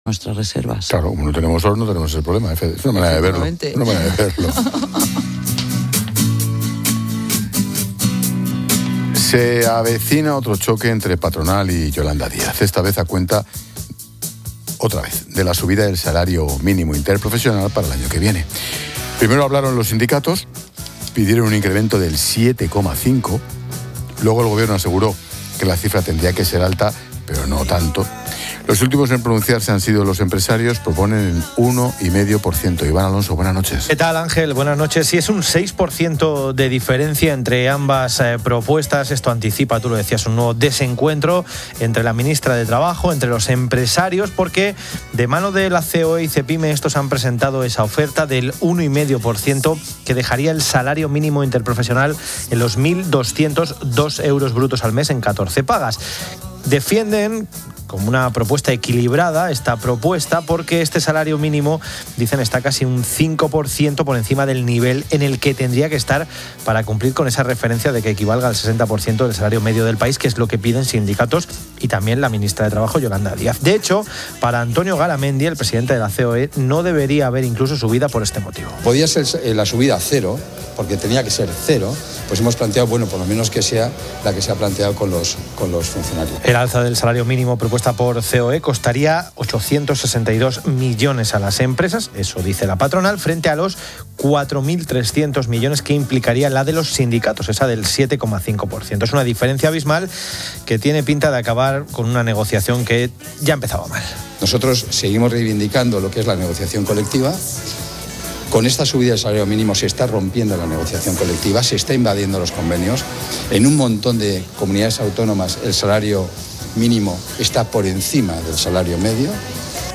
Pilar García de la Granja, experta económica